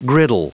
Prononciation du mot griddle en anglais (fichier audio)
Prononciation du mot : griddle